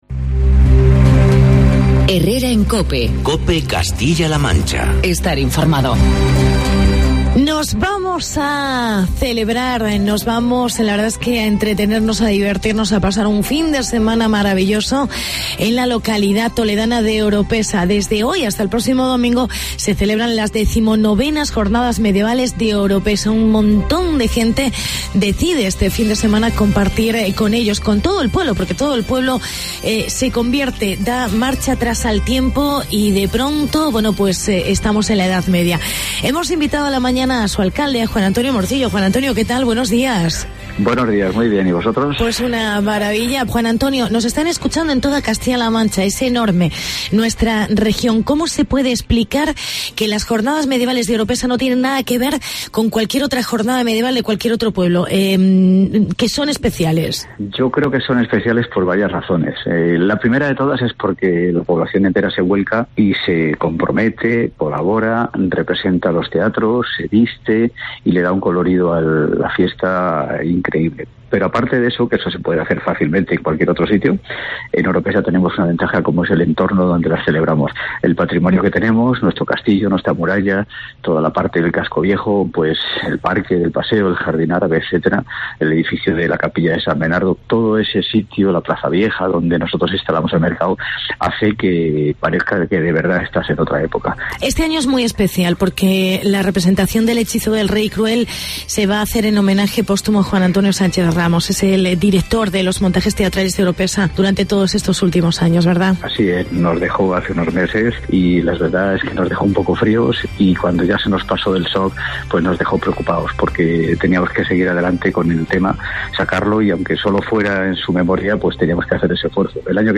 Entrevista con el alcalde Juan Antonio Morcillo